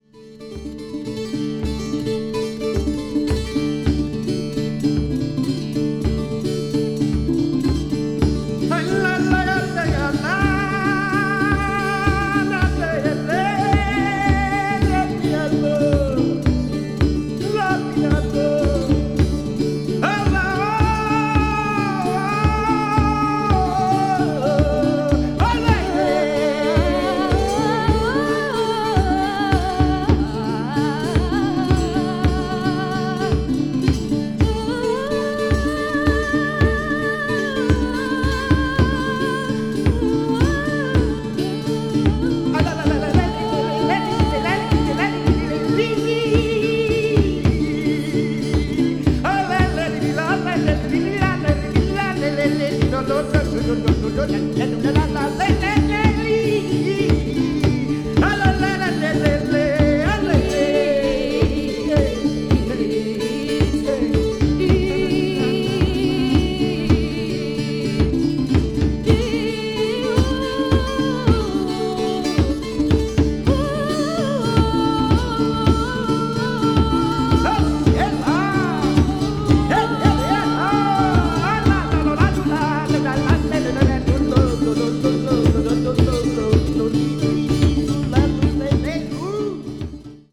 独特のエコー処理が施されたたおやかな音響が心地良いB5やB6がお薦め。